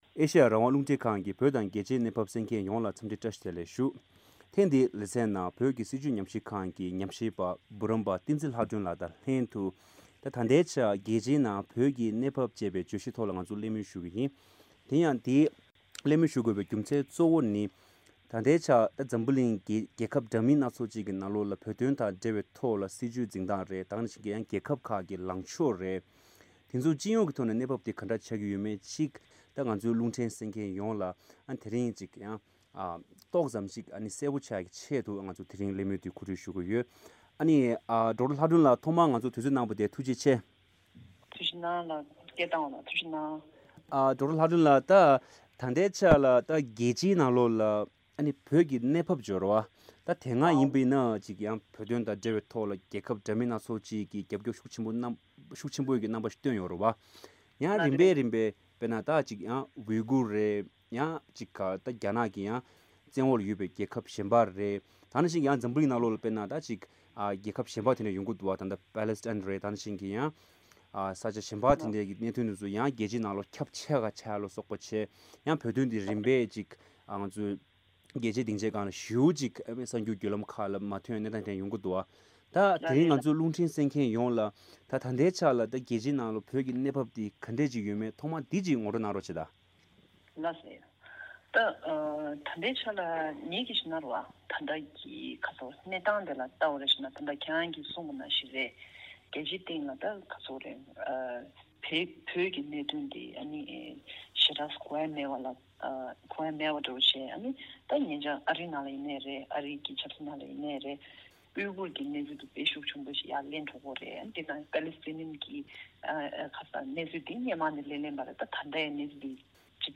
བཀའ་འདྲི་ཞུས་པ།